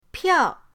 piao4.mp3